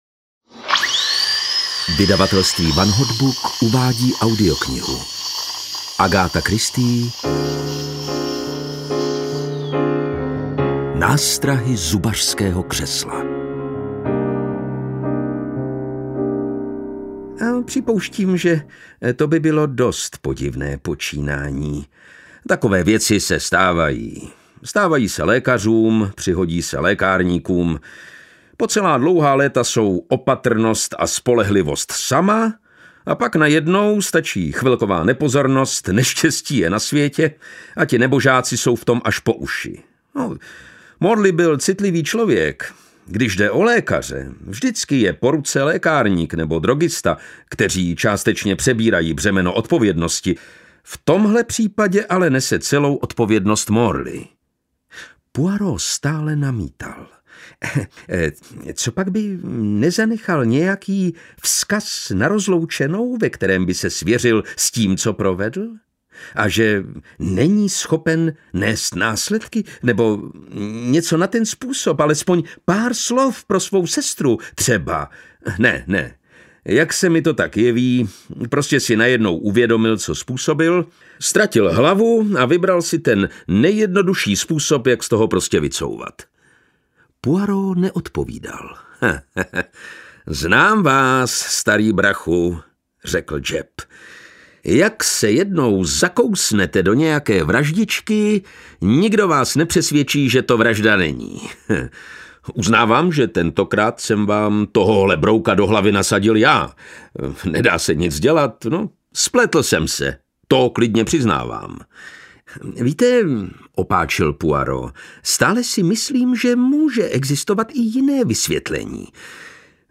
Nástrahy zubařského křesla audiokniha
Ukázka z knihy
• InterpretLukáš Hlavica